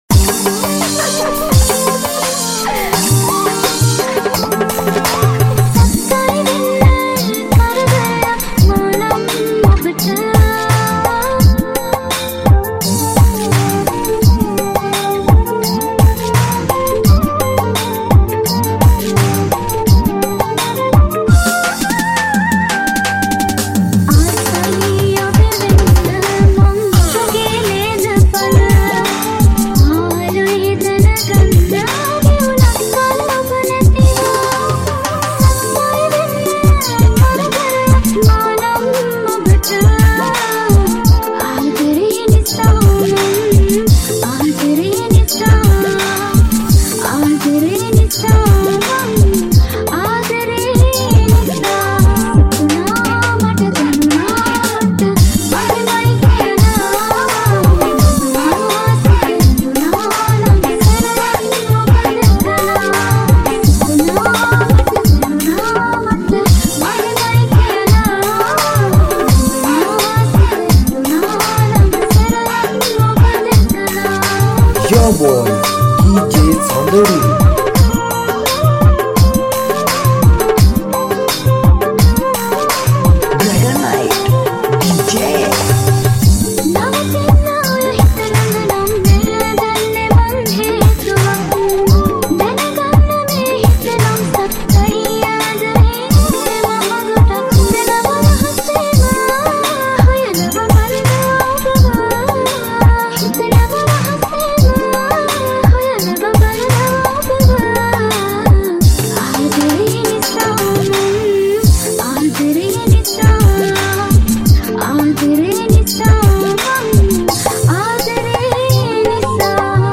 High quality Sri Lankan remix MP3 (7.7).